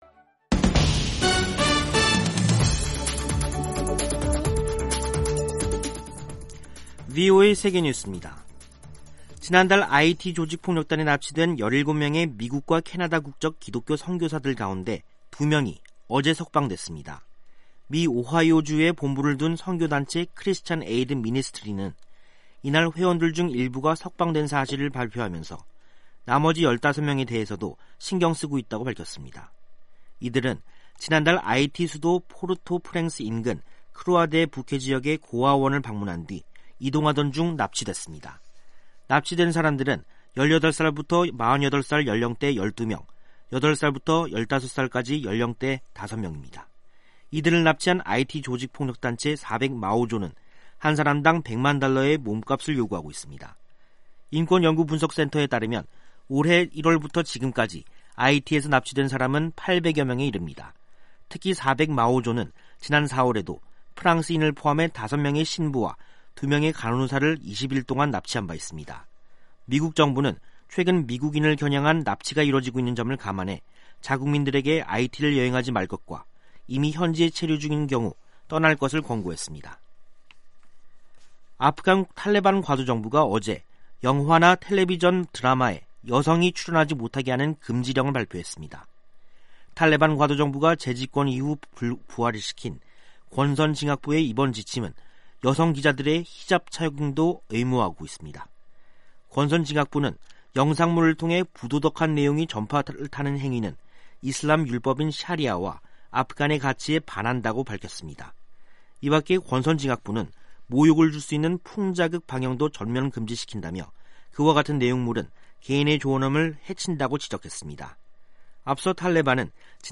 세계 뉴스와 함께 미국의 모든 것을 소개하는 '생방송 여기는 워싱턴입니다', 2021년 11월 22일 저녁 방송입니다. '지구촌 오늘'에서는 수단 군부가 쿠데타로 권좌에서 축출했던 총리를 복귀시키고 구금했던 각료들을 석방한 소식, '아메리카 나우'에서는 미국 질병통제예방센터(CDC)가 코로나 백신 부스터샷 사용 대상을 모든 성인으로 확대한 소식 전해드립니다.